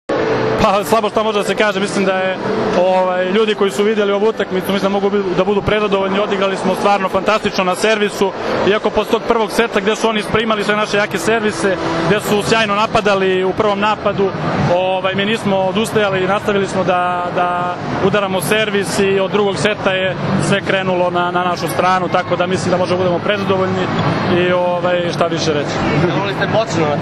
IZJAVA SREĆKA LISINCA